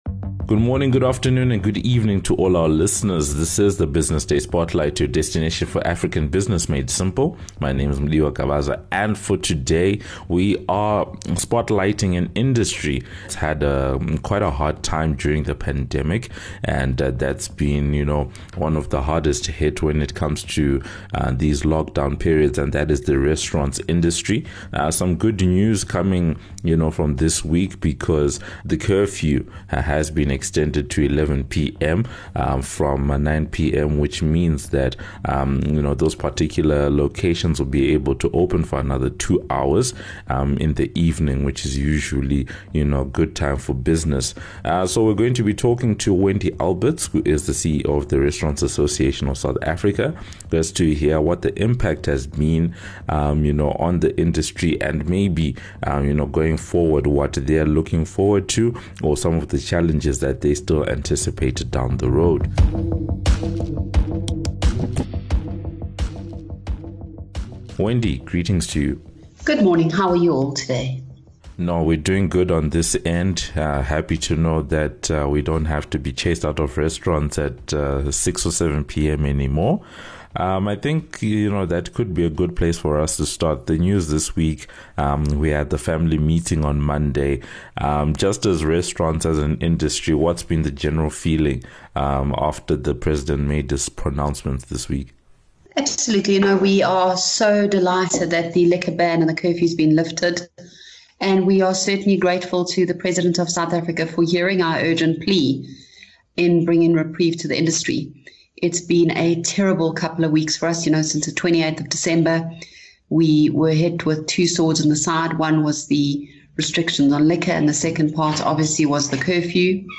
The discussion focuses on the plight of restaurant owners during this time, the hardship faced by employees and suppliers, the alleged abuse of liquor licenses by different businesses, the burden of negotiating with landlords and lenders, the need for greater support of the industry and ways in which operators can ensure compliance, thereby reducing the risk of future restrictions on the industry. Listen in to hear the full discussion and thoughts around these and other questions.